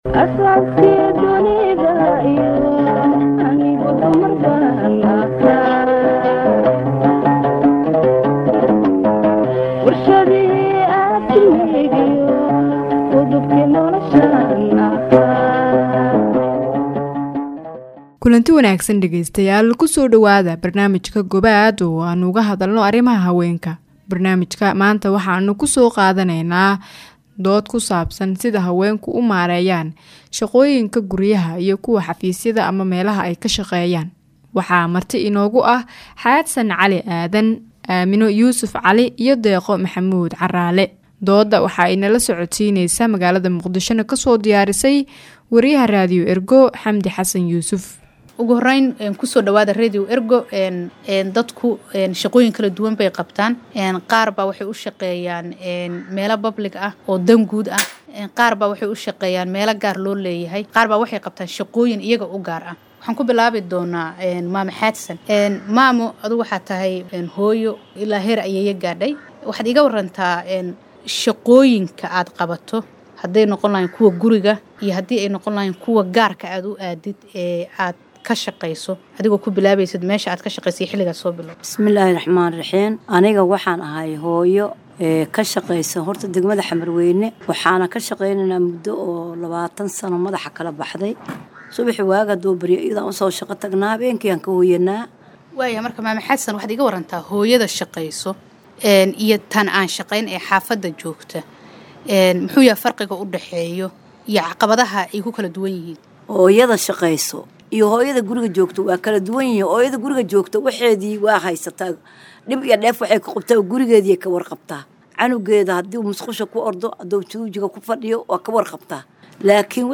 Dhegeyso barnaamijka Gobaad oo aan uga hadalno arrimaha haweenka. Barnaamijka maanta waxaan ku soo qaadaneynaa dood ku saabsan sida ay haweenka iskugu maareeyaan shaqooyinka guriga iyo xafiisyada.